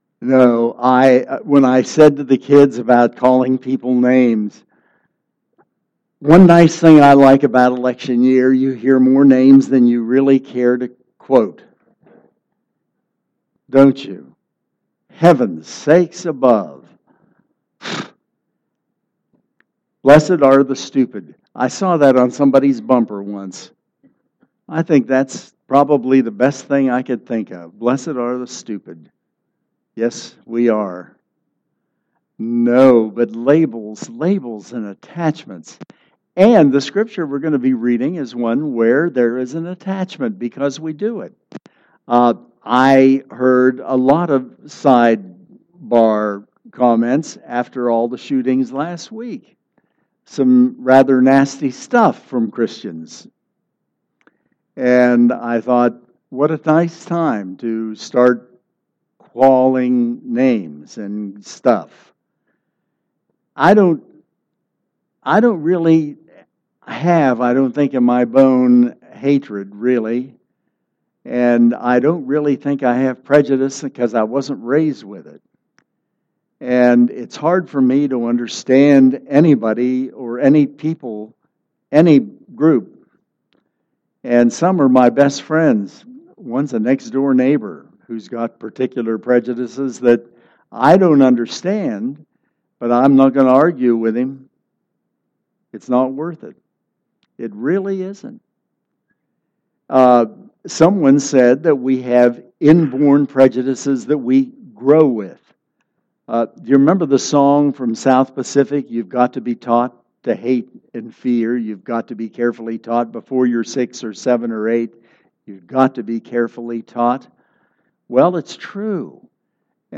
Sermon Tags